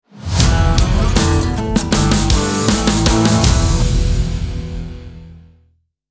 Download Podcast Beginning sound effect for free.